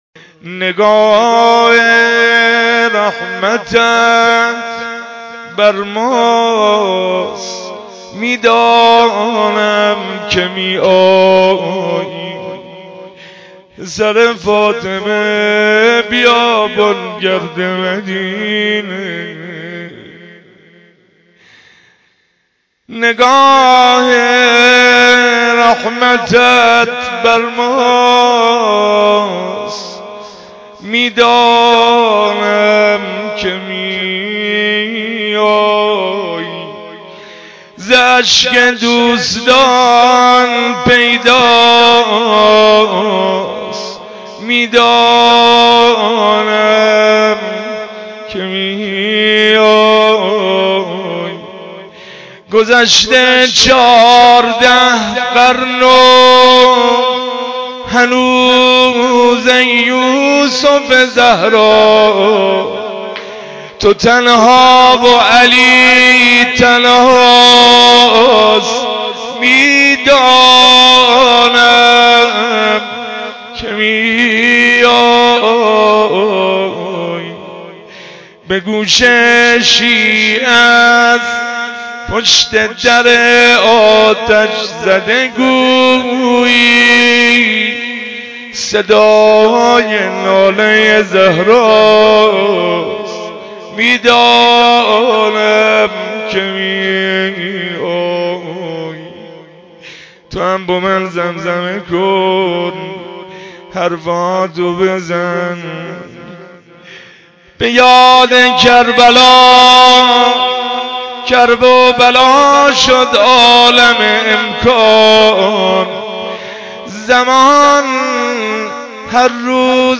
جلسه هفتگی96/8/24